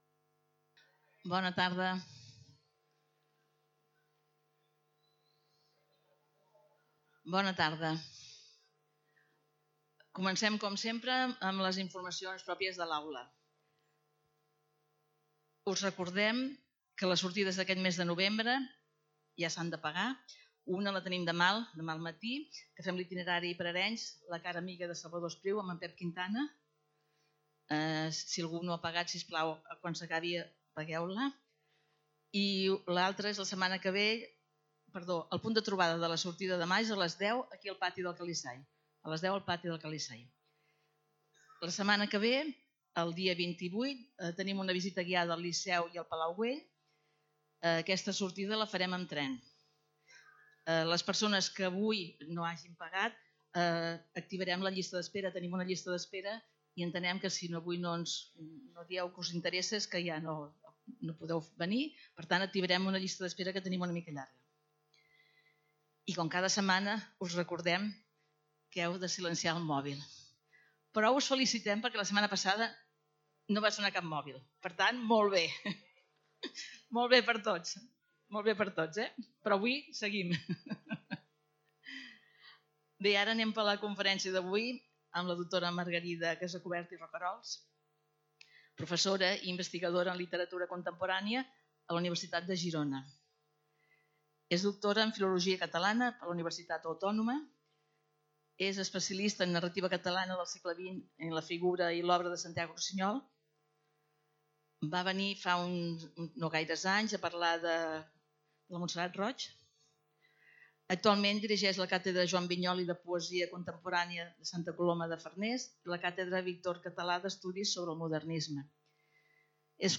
Lloc: Centre Cultural Calisay
Categoria: Conferències